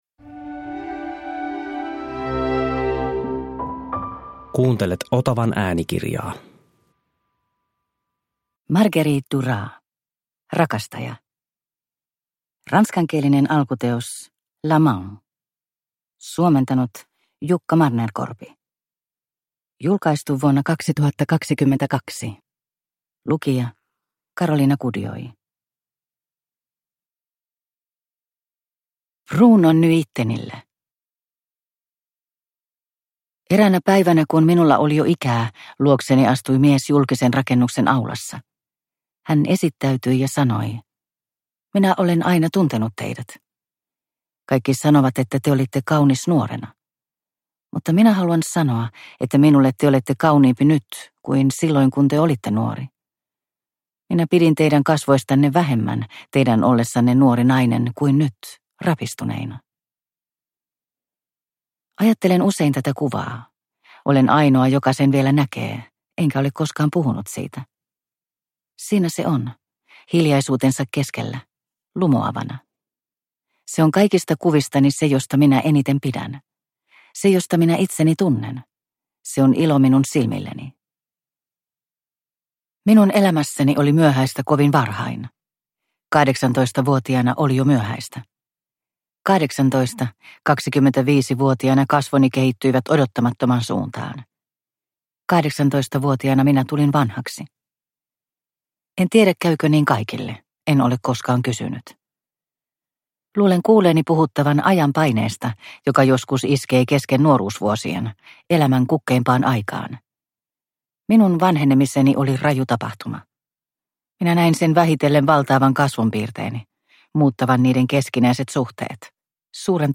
Rakastaja – Ljudbok – Laddas ner